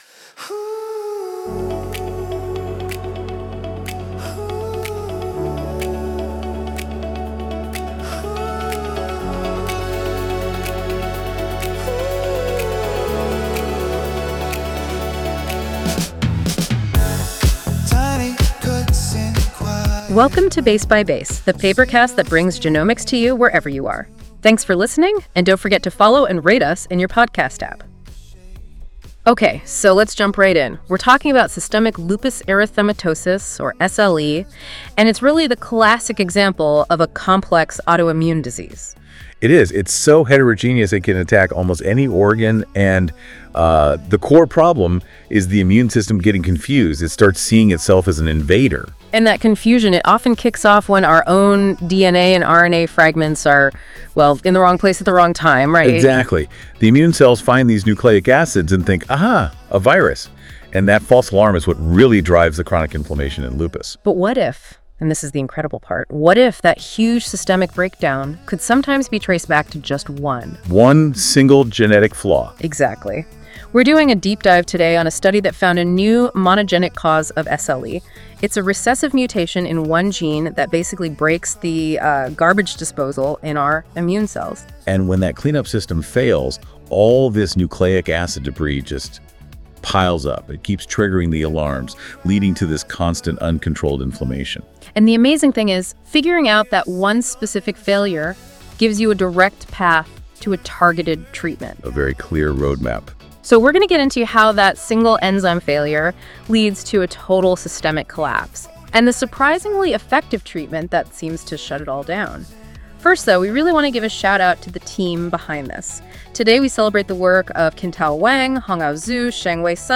PLD4 Deficiency and Lupus: When Nuclease Failure Ignites Autoimmunity Music:Enjoy the music based on this article at the end of the episode.